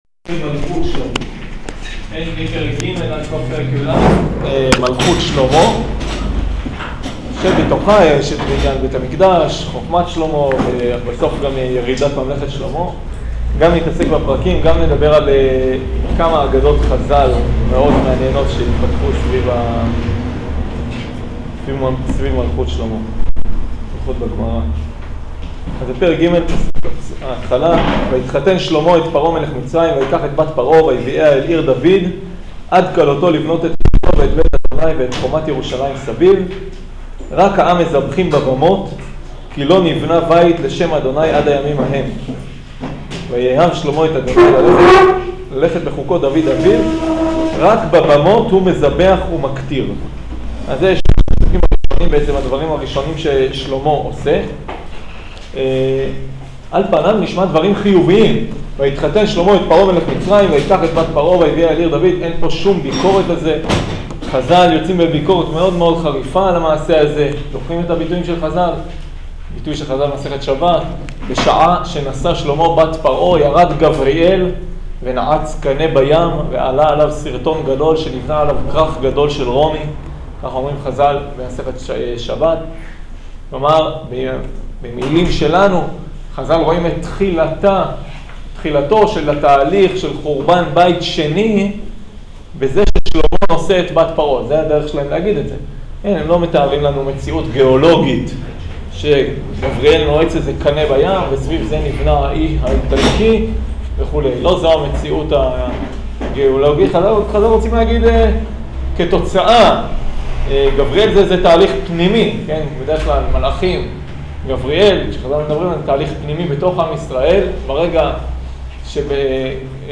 שיעור פרק ג'